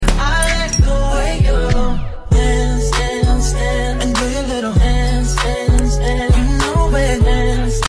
Pimple Popping On Head In Sound Effects Free Download